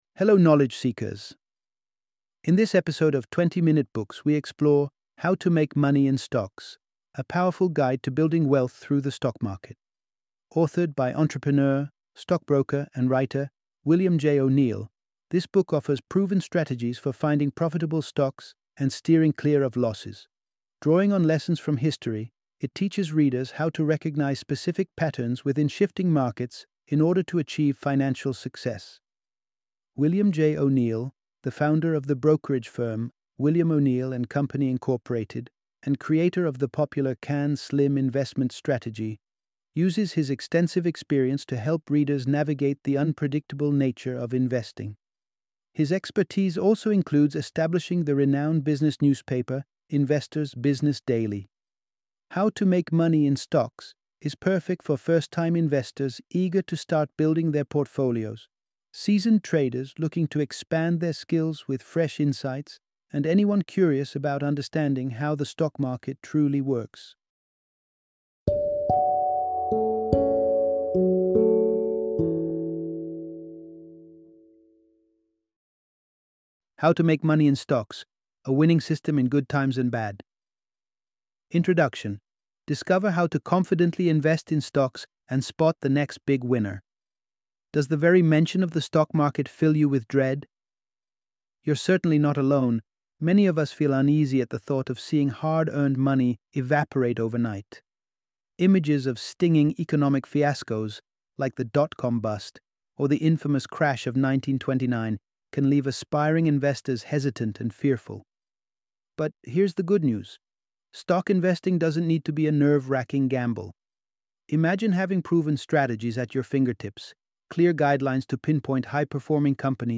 How to Make Money in Stocks - Audiobook Summary